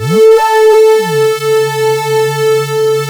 OSCAR 10 A4.wav